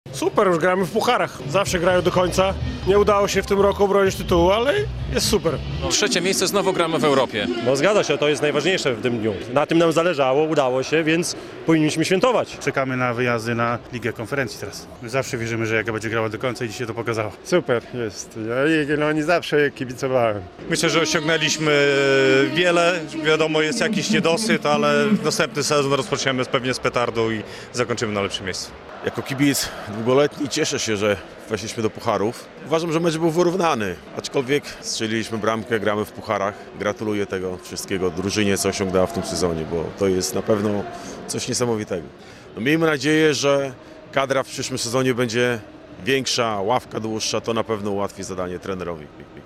Kibice oceniają mecz